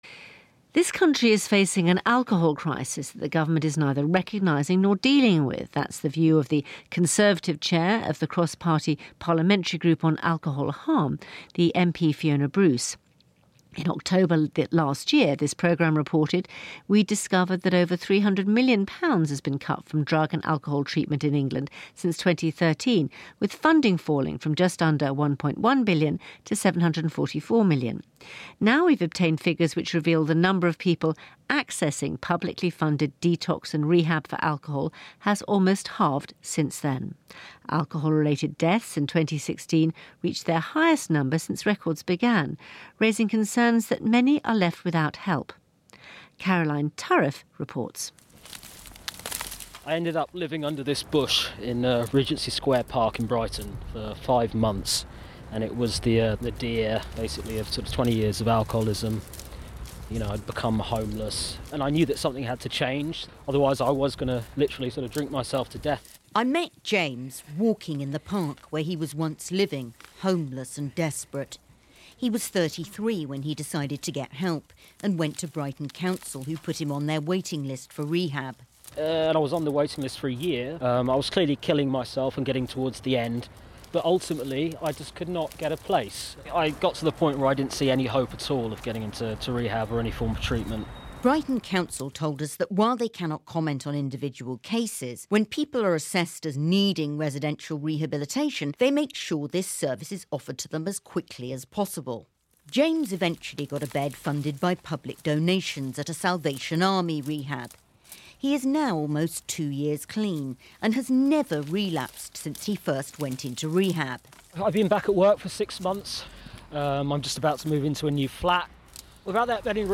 My piece on the UK’s alcohol crisis that the government is doing nothing about according to one of their own MPs headlines the BBC news on the World at One on Radio 4.